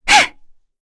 Frey-Vox_Attack3.wav